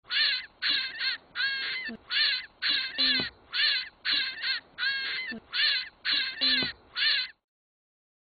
Звуки куницы
Крик лесной куницы